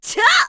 Laudia-Vox_Attack2_kr.wav